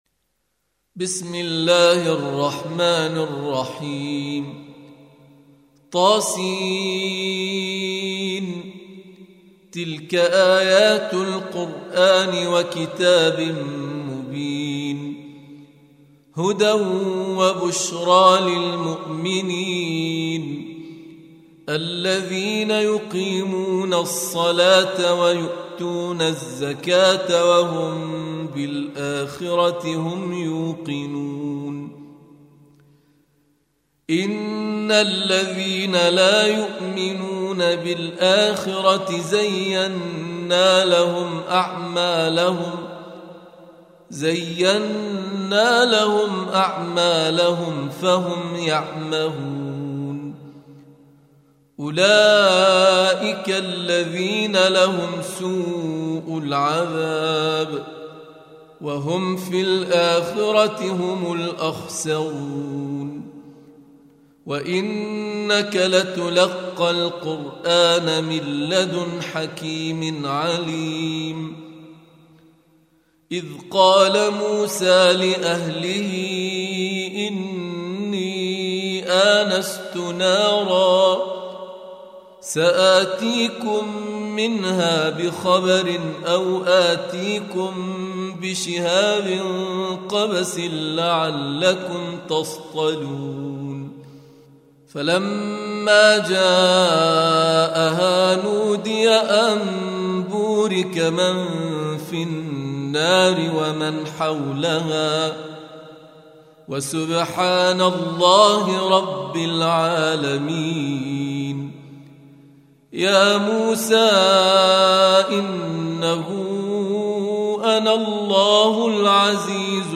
Surah Repeating تكرار السورة Download Surah حمّل السورة Reciting Murattalah Audio for 27. Surah An-Naml سورة النّمل N.B *Surah Includes Al-Basmalah Reciters Sequents تتابع التلاوات Reciters Repeats تكرار التلاوات